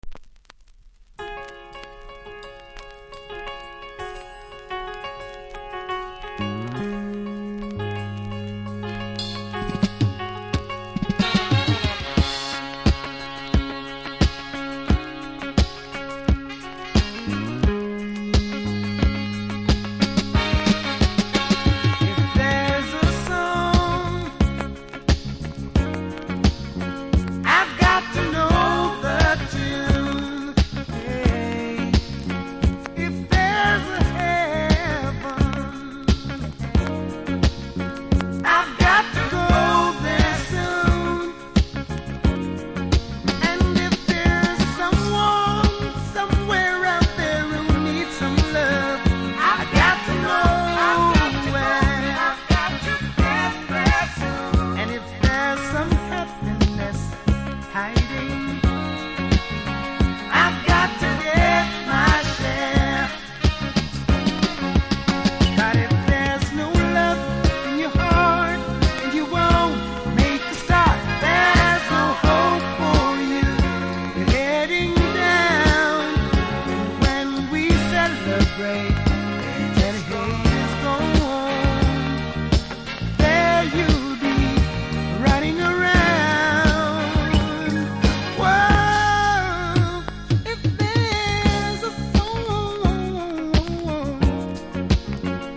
残念ながらこちらサイドは深いキズ1本ありノイズ感じますので試聴で確認下さい。